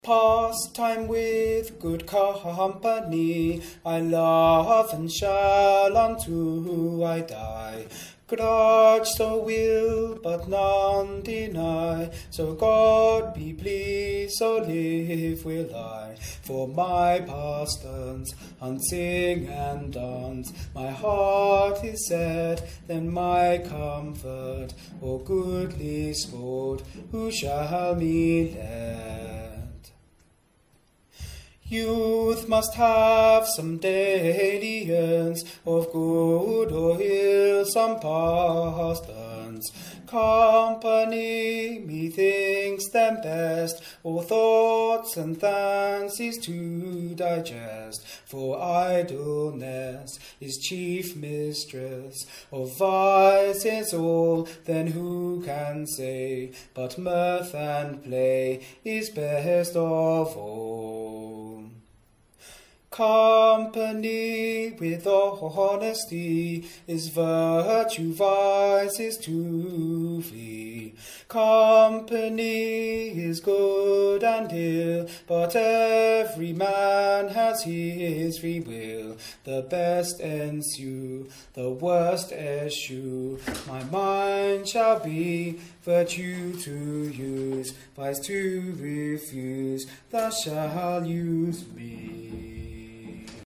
UOMINI